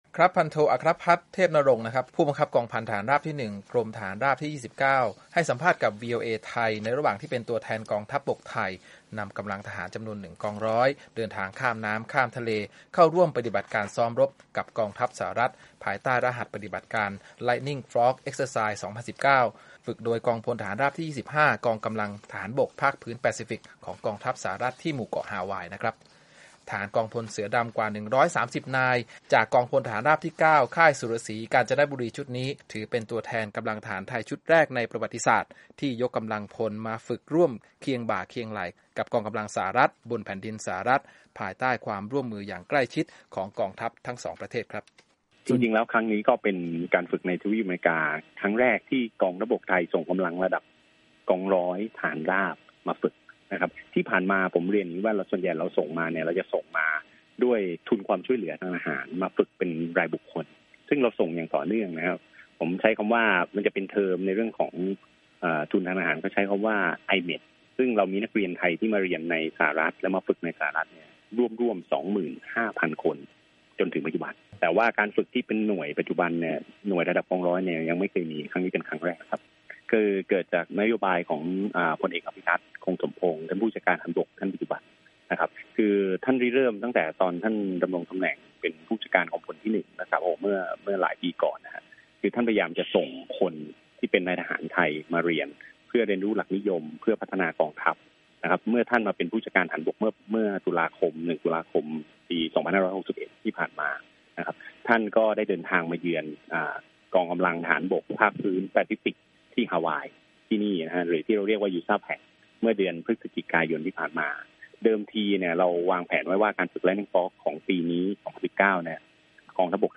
Thai soldiers take part in the Lightning Forge Exercise 2019, a large-scale training exercise, on Oahu, Hawaii, U.S.A.